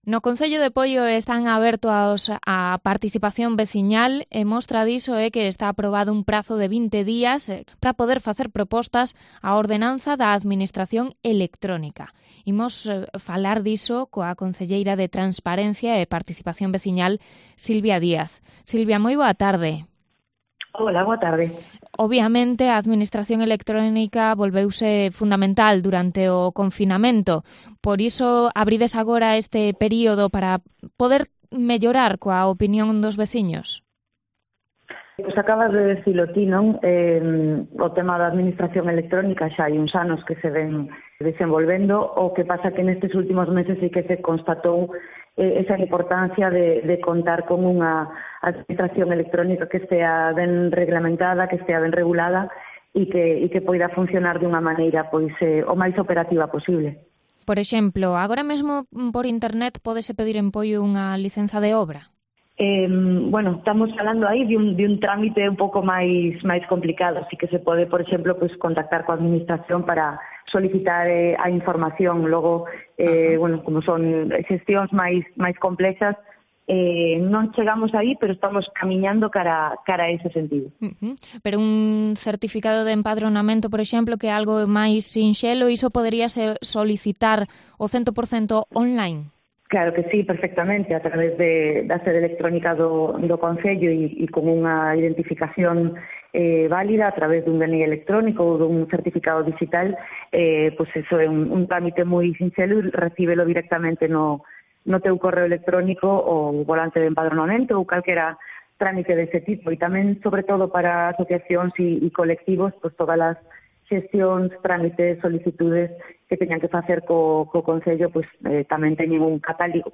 Entrevista a la concejala de Participación Vecinal de Poio, Silvia Díaz
Próximamente se abrirá un plazo de 20 días para que los vecinos hagan sugerencias a la ordenanza de administración electrónica de Poio. En COPE + Pontevedra, la concejala Silvia Díaz ha explicado alguno de los trámites que se pueden gestionar a través de internet ahora mismo.